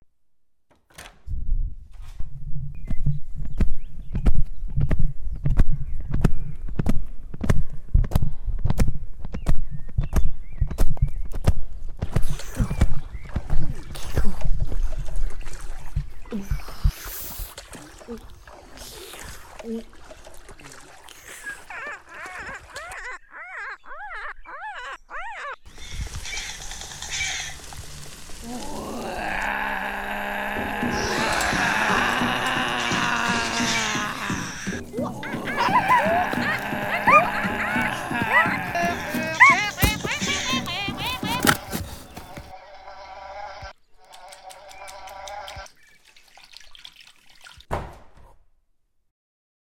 In jedem Raum erlebst du eine Geräuscheatmosphäre mit vielen verschiedenen Geräuschen.
Geraaeuscherei_-_Ur-_und_Untierraum.mp3